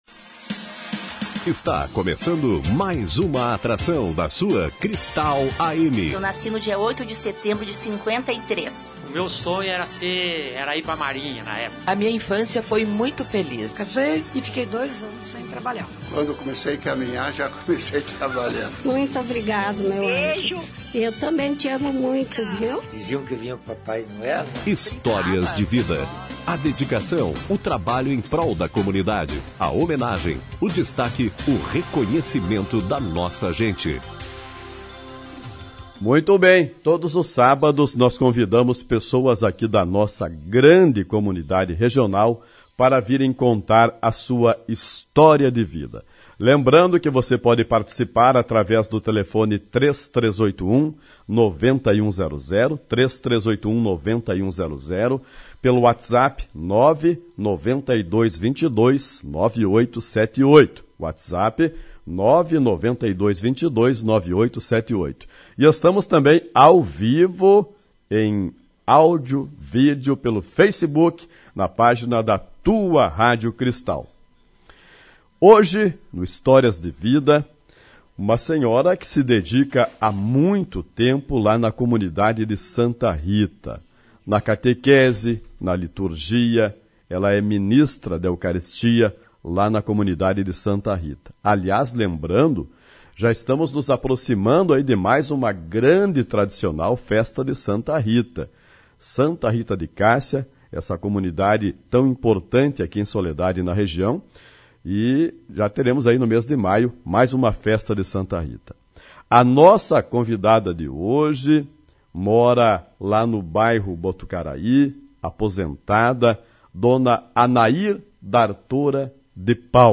A Tua Rádio Cristal de Soledade realiza todos os sábados, durante o espaço "Cristal Revista", uma entrevista especial, como forma de homenagear pessoas da comunidade regional.